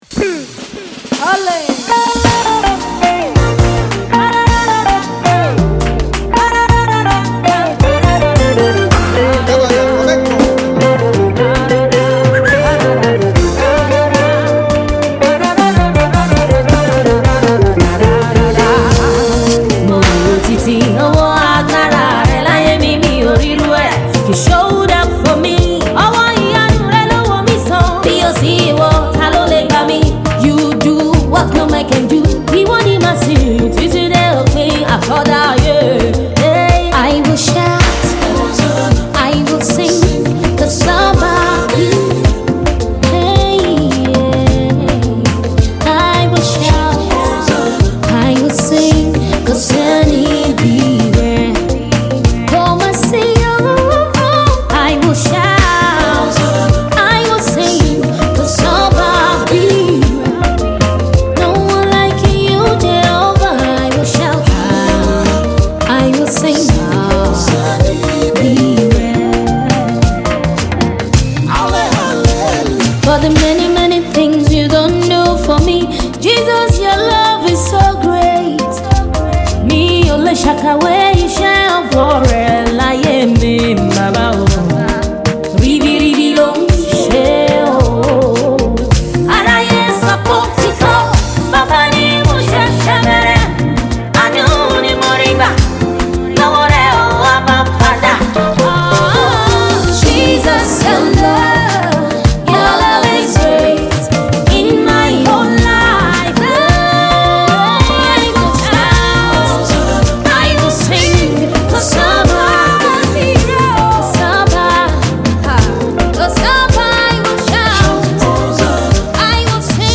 Gospel Music
gospel single